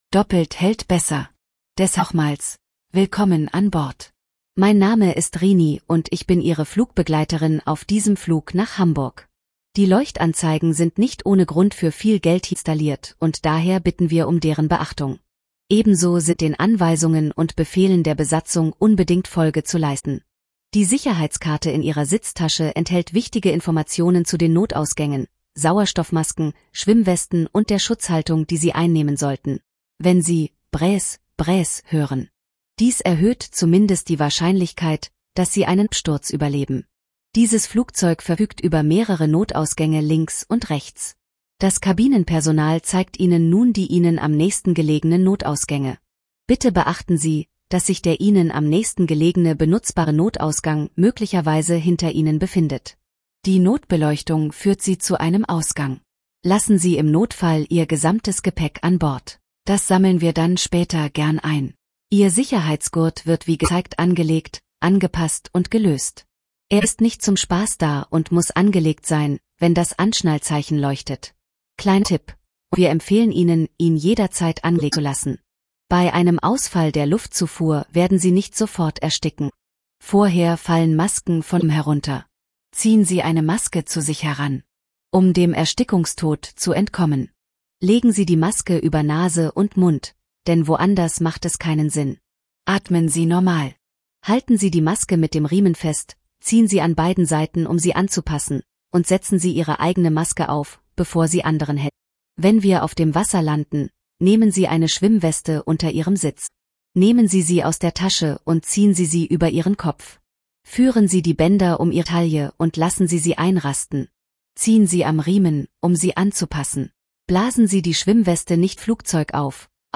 SafetyBriefing.ogg